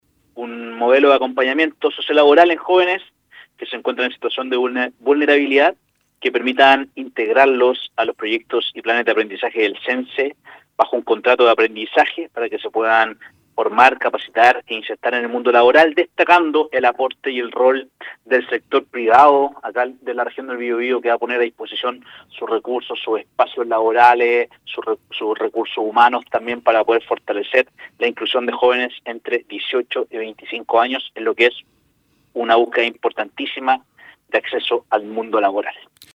El director nacional del INJUV, Juan Pablo Duhalde, en entrevista con Radio UdeC, explicó en general cómo funciona este piloto.